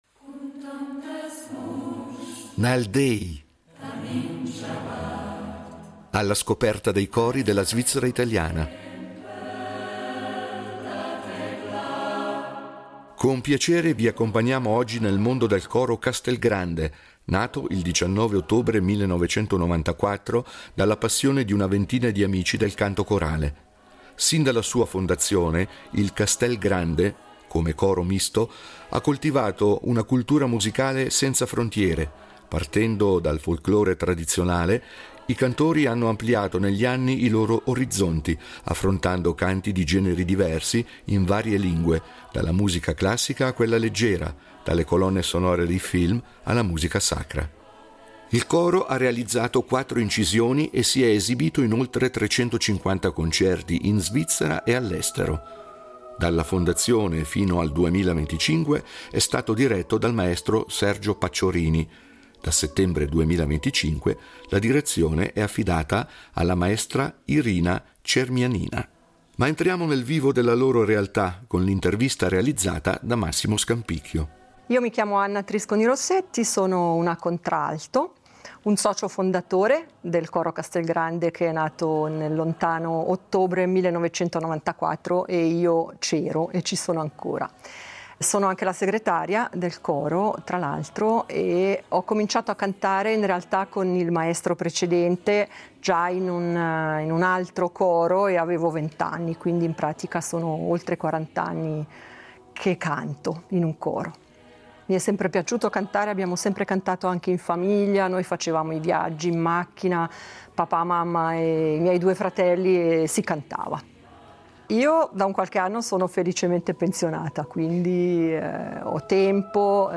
coro misto
spaziando dal folklore tradizionale alla musica classica, leggera e sacra